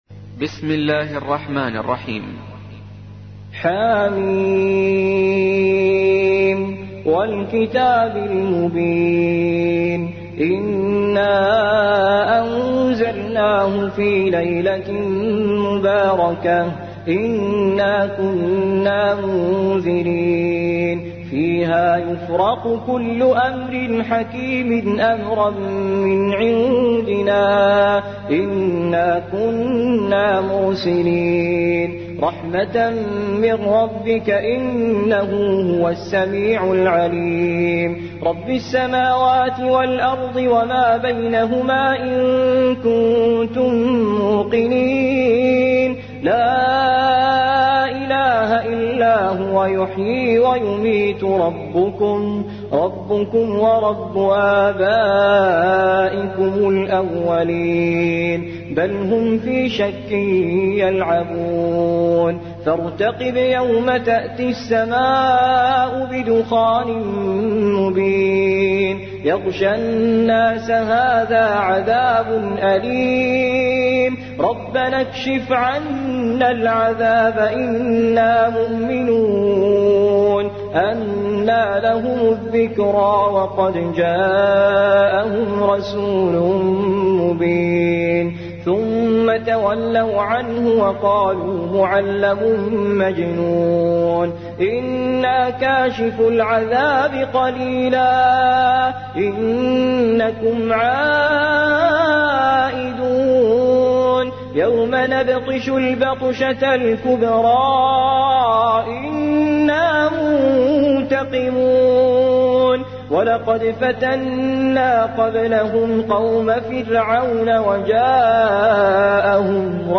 44. سورة الدخان / القارئ